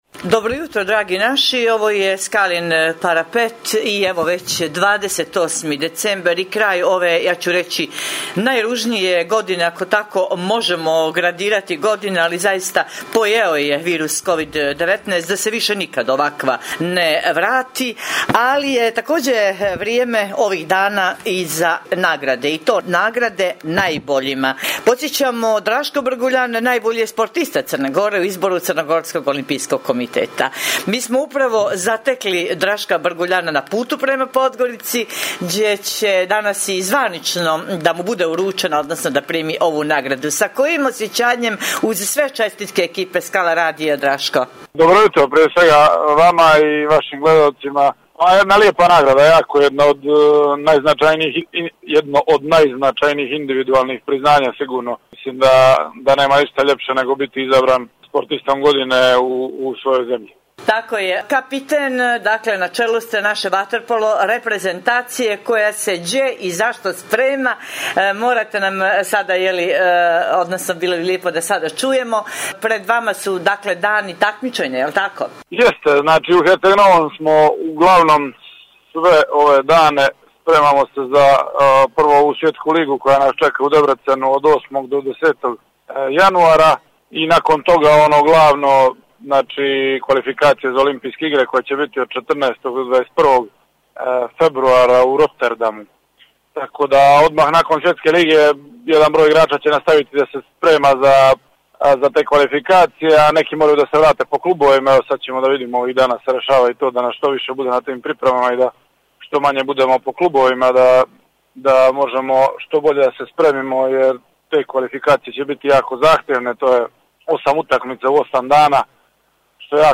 Gost emisije vaterpolista Draško Brguljan, najbolji sportista u 2020.
Poštujući pravila nadležnih zbog epidemije korona virusa Skala radio će emisiju “Skalin parapet” emitovati u izmijenjenoj formi i u skraćenom trajanju, budući da nema gostovanja u studiju Skala radija do daljnjeg.
Stoga ćemo razgovore obavljati posredstvom elektronske komunikacije i telefonom, kako bi javnost bila pravovremeno informisana o svemu što cijenimo aktuelnim, preventivnim i edukativnim u danima kada moramo biti doma.